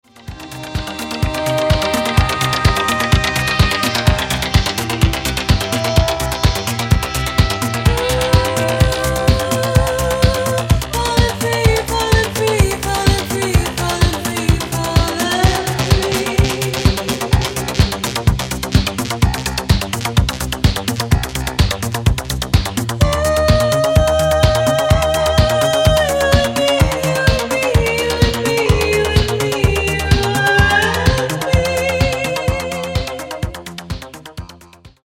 Genere:   Disco| Funky | Soul |